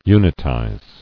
[u·nit·ize]